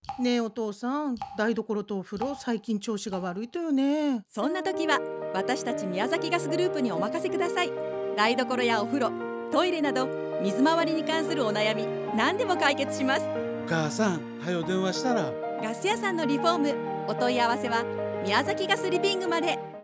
"ガス屋さんのリフォーム”ラジオCM放送のご案内
MiyaGasLivingRadiocm.wav